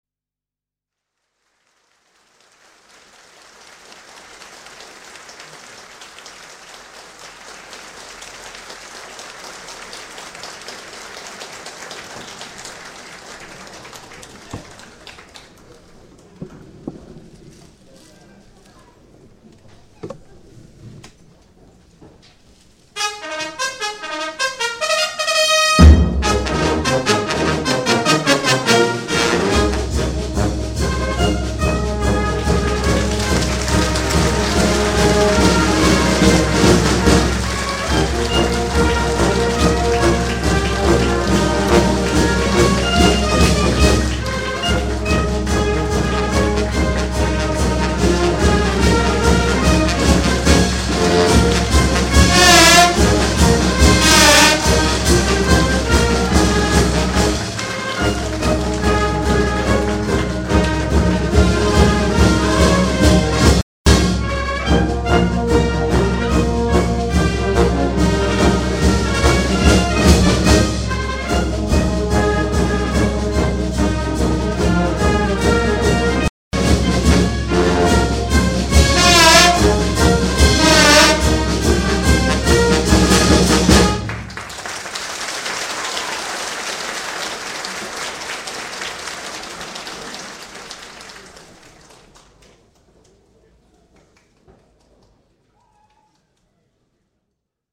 9.Montauro's band
Montauro's  band.mp3